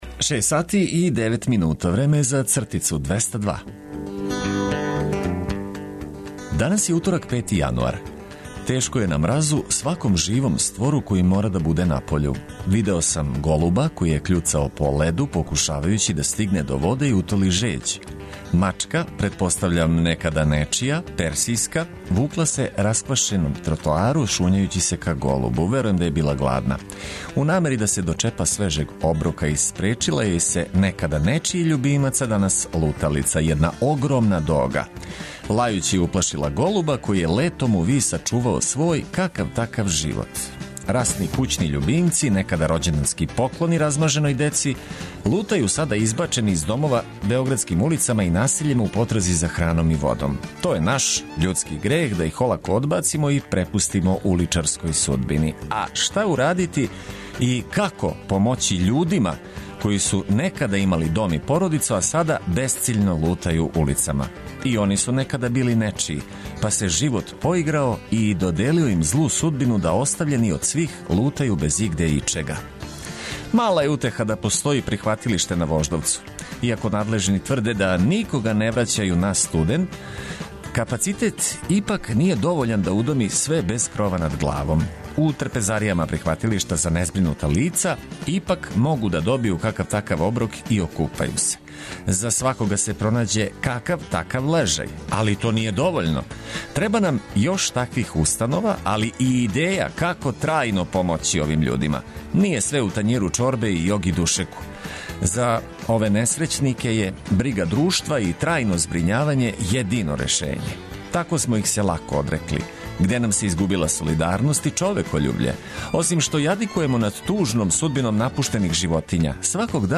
У сусрет манифестацијама током предстојећег Божића, уз одличну музику и ведро расположење.